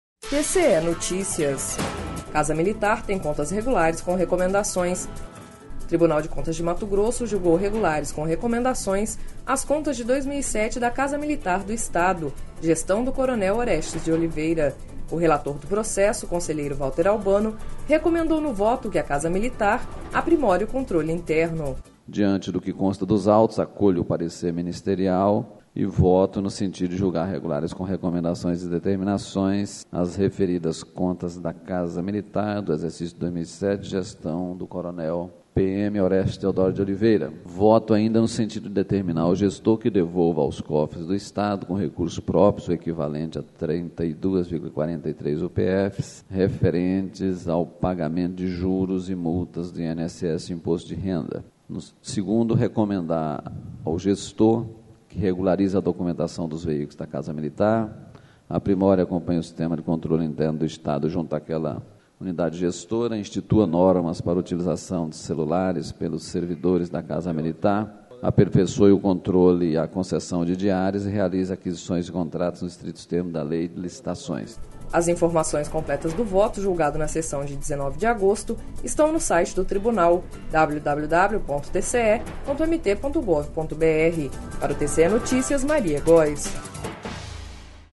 O relator do processo, conselheiro Valter Albano, recomendou no voto que a Casa Militar aprimore o controle interno.// Sonora: Valter Albano – conselheiro TCE-MT